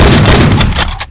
riotgun.wav